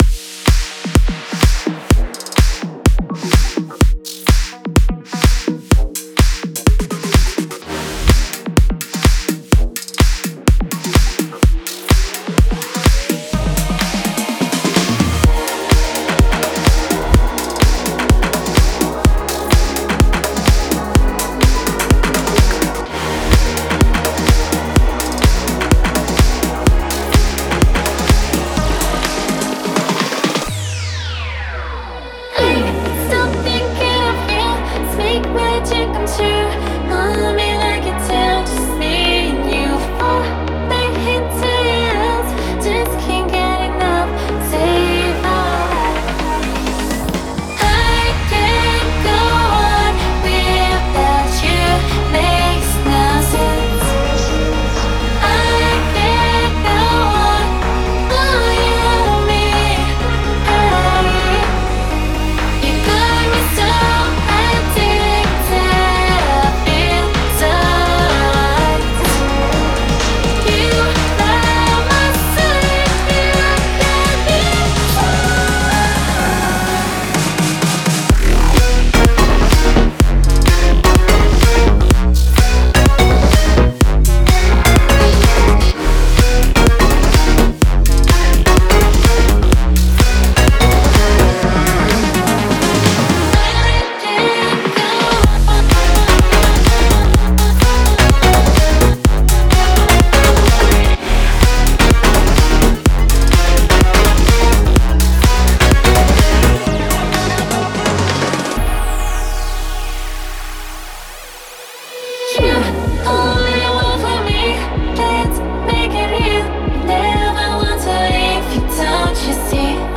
энергичных битов, создавая запоминающийся ритм